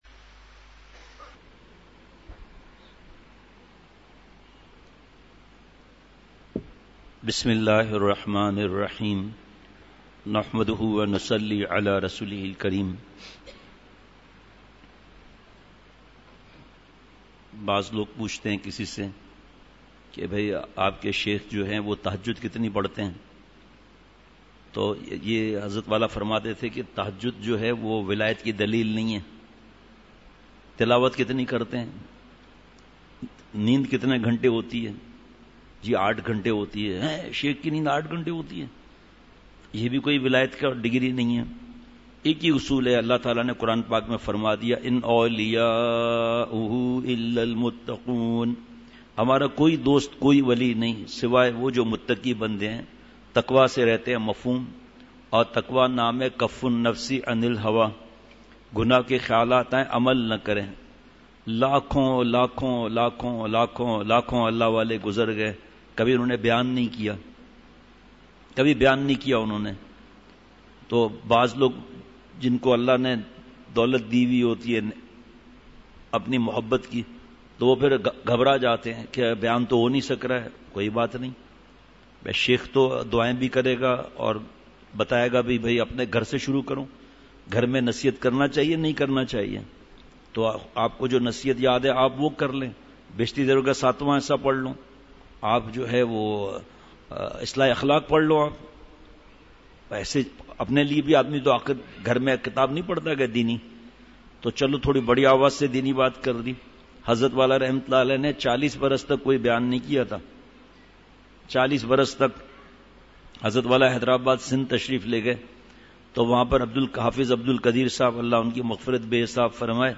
مجلس ۱۱ جولائی ۲۰۱۹ء بعد عصر : اللہ والوں کے پاس آنا جانا رکھیں !